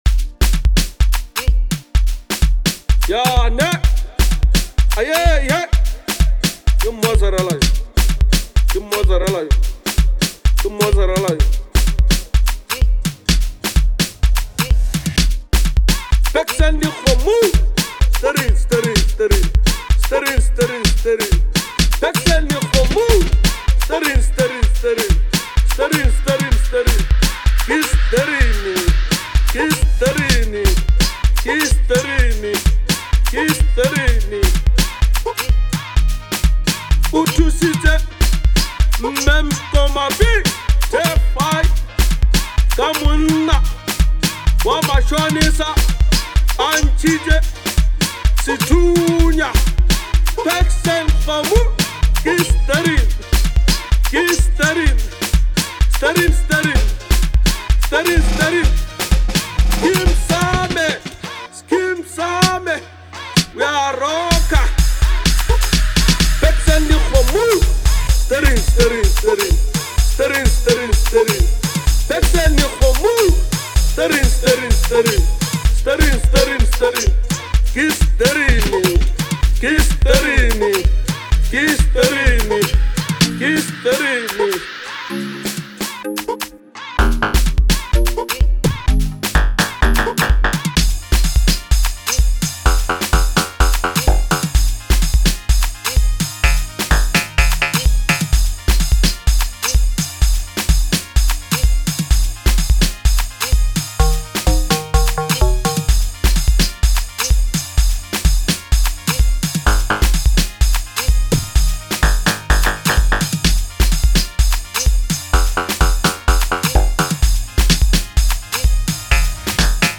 a vibrant fusion of infectious beats and rhythmic melodies